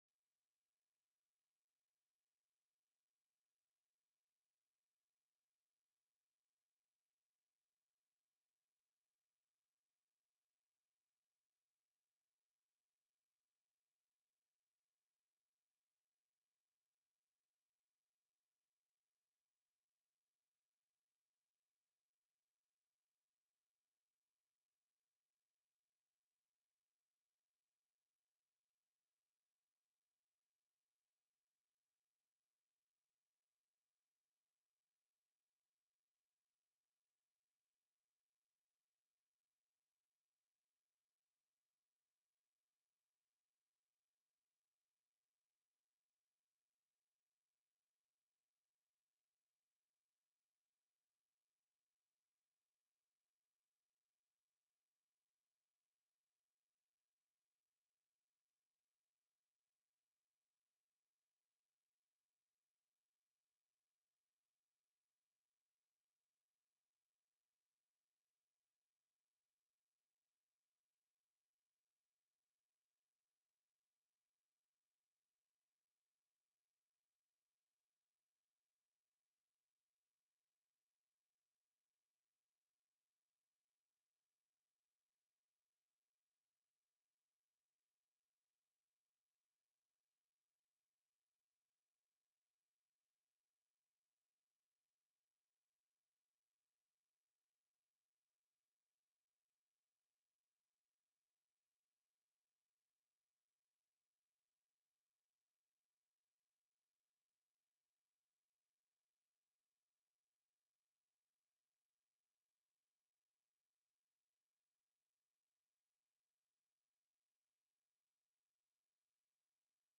The audio recordings are captured by our records offices as the official record of the meeting and will have more accurate timestamps.
+ Bills Previously Heard/Scheduled TELECONFERENCED